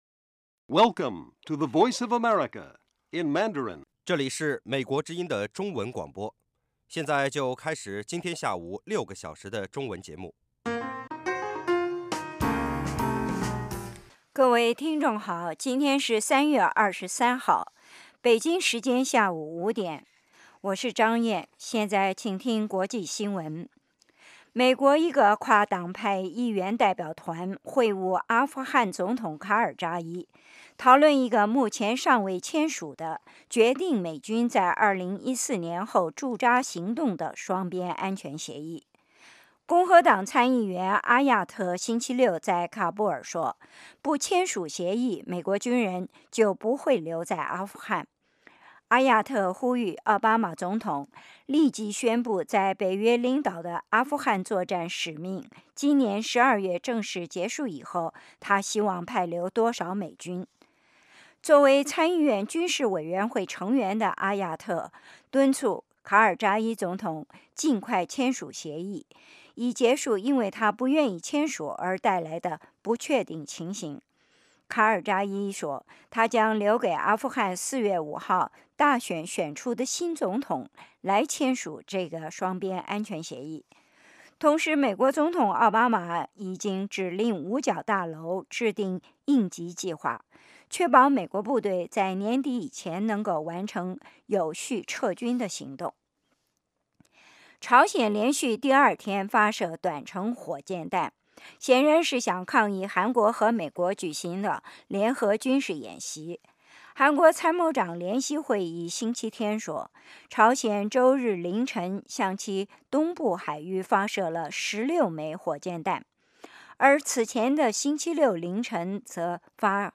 国际新闻 英语教学 社论 北京时间: 下午5点 格林威治标准时间: 0900 节目长度 : 60 收听: mp3